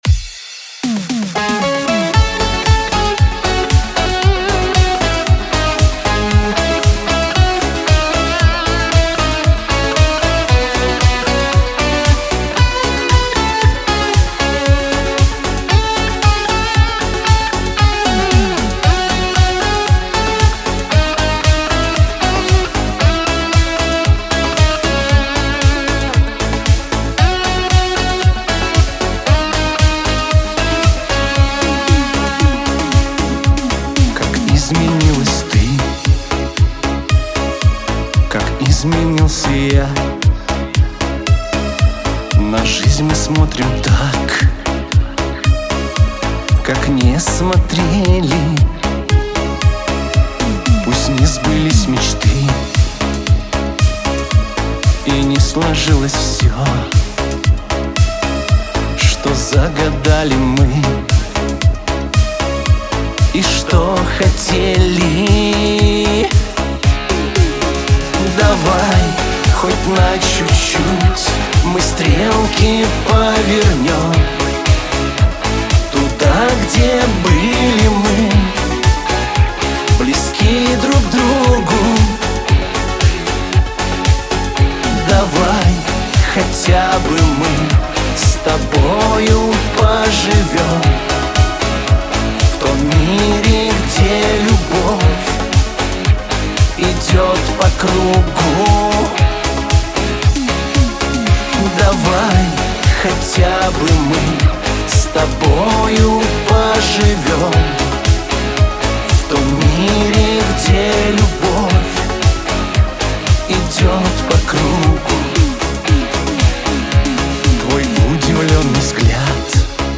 диско , pop
эстрада